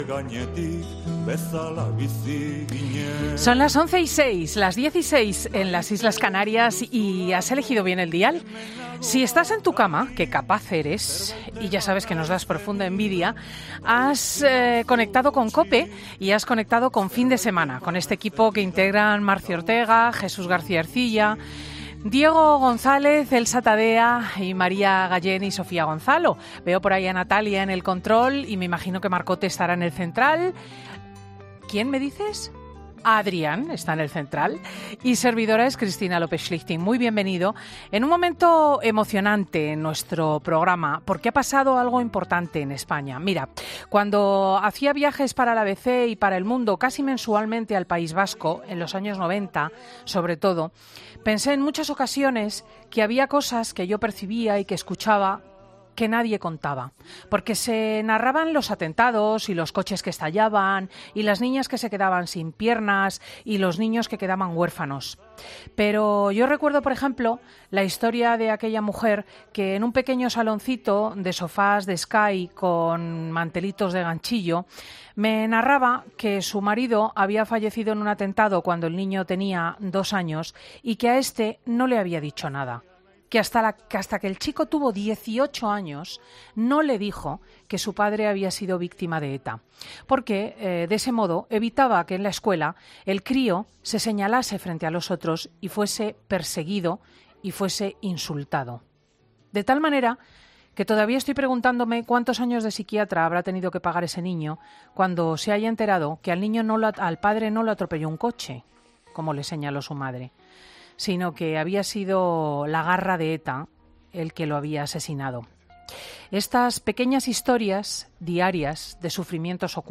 El escritor Fernando Aramburu presenta 'Patria' en 'Fin de semana'